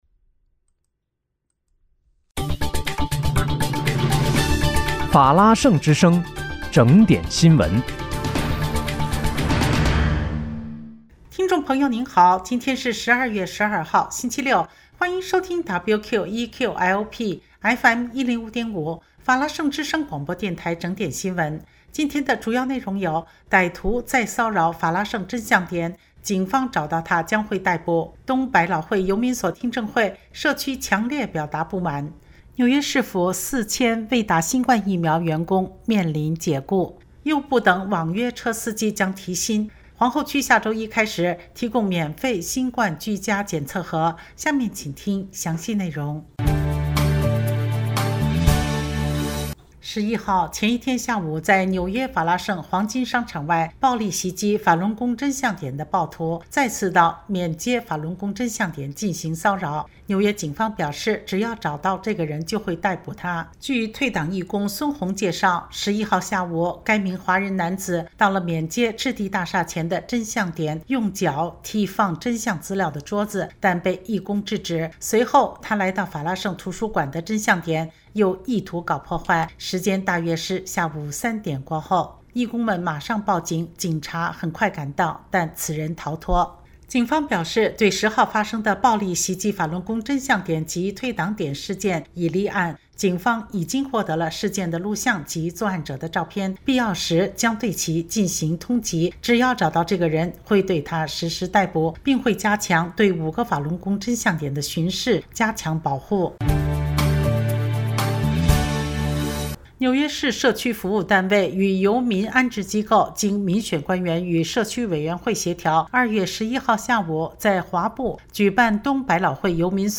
2月12日（星期六）纽约整点新闻